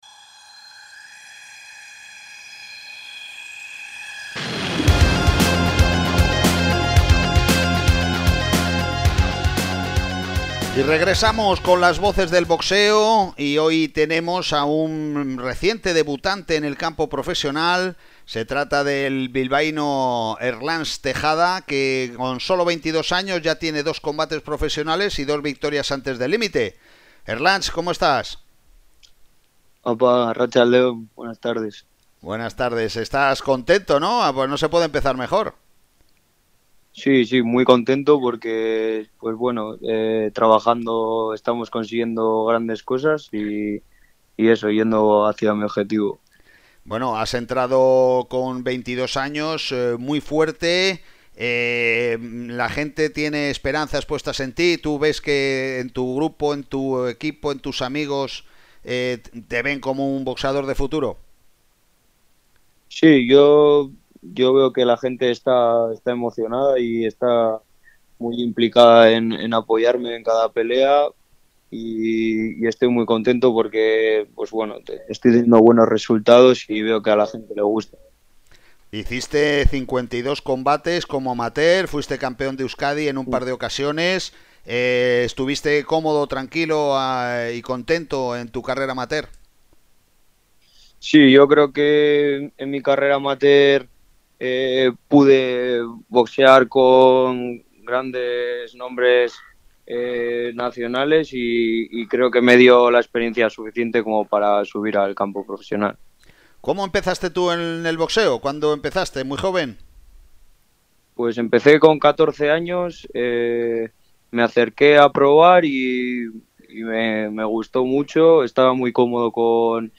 Volvemos tras el verano con la sección semanal de los jueves llamada «Las Voces del Boxeo», en la que los principales personajes de la actualidad de nuestro pugilismo son entrevistados radiofónicamente